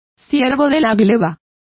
Complete with pronunciation of the translation of serfs.